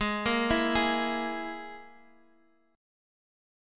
G#sus2#5 chord